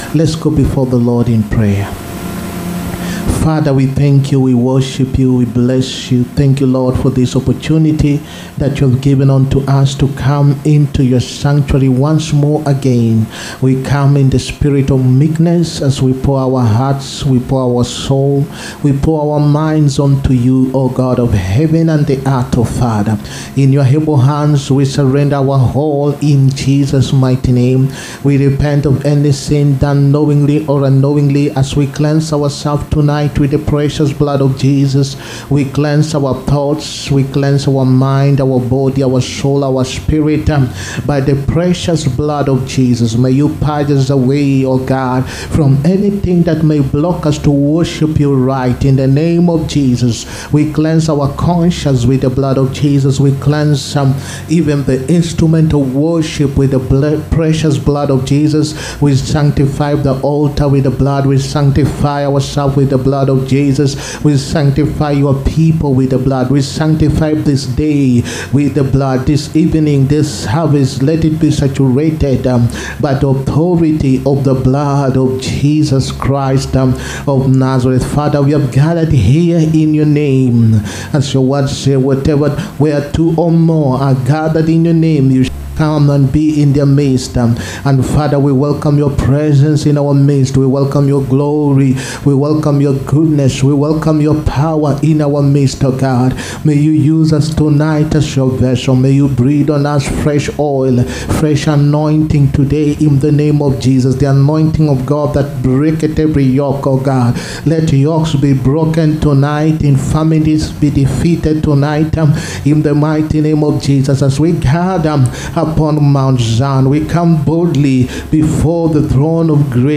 HEALING-PROPHETIC-AND-DELIVERANCE-SERVICE.-5TH-JULY-2025.mp3